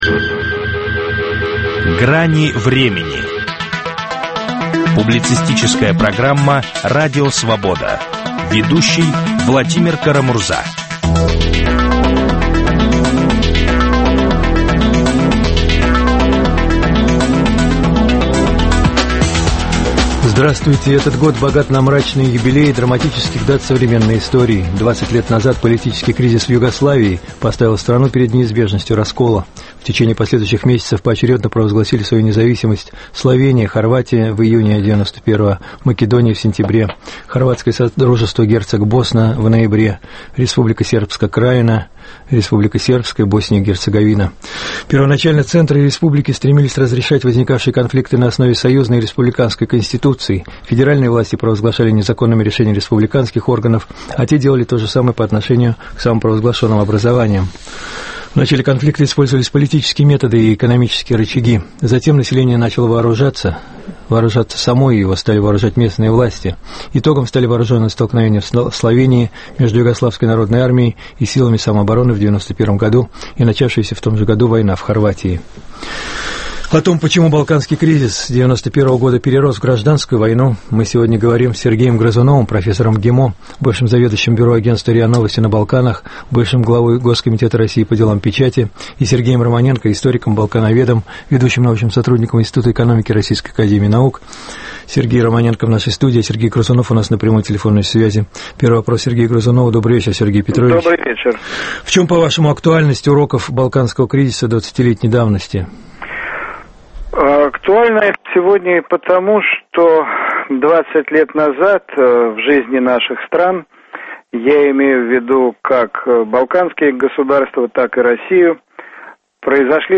Почему балканский кризис 91-го года перерос в гражданскую войну? Об этом спорят политологи